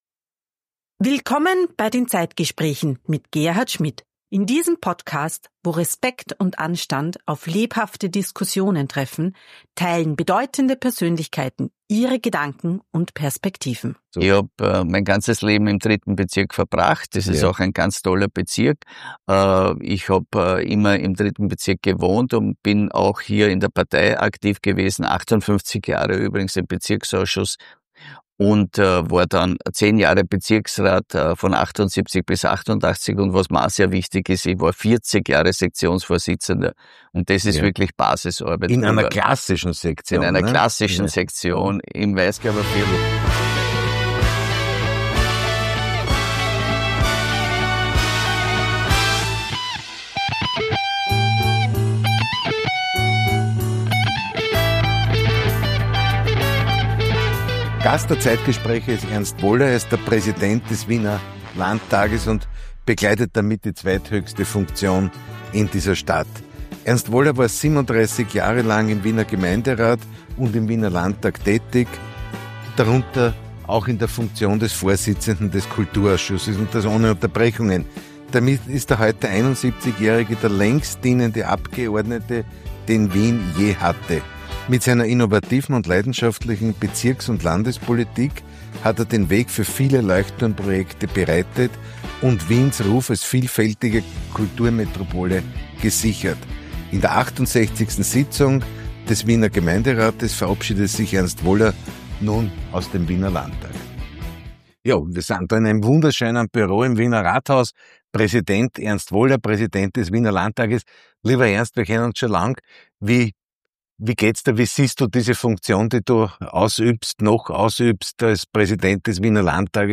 Ein Gespräch über Kultur, Politik und die Liebe zu Wien – jetzt reinhören!